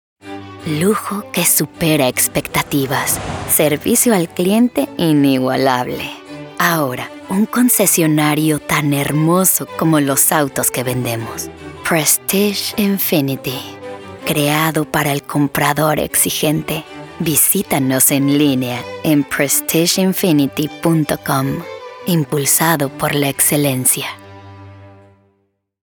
Professional Spanish voice over for local automotive ads, dealership promos, and national campaigns.
Sophisticated Read